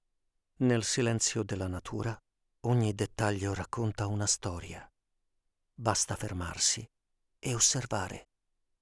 0402IT_Documentary_Deep.mp3